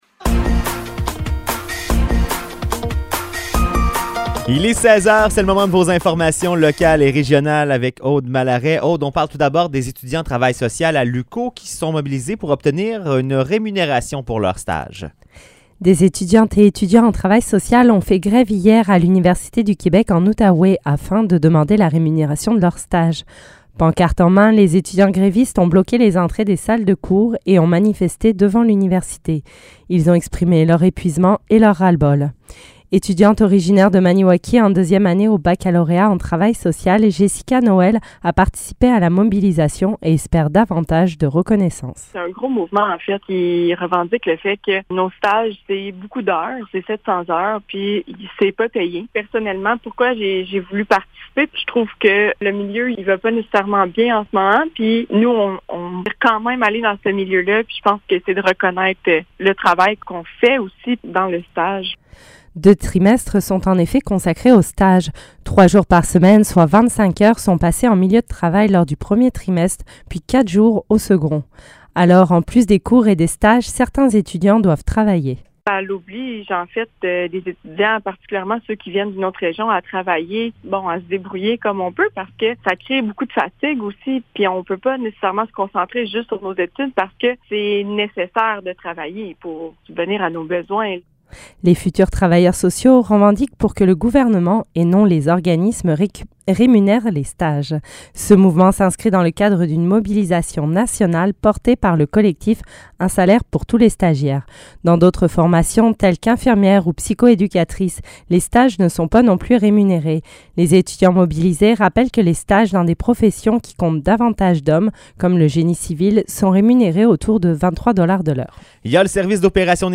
Nouvelles locales - 24 novembre 2022 - 16 h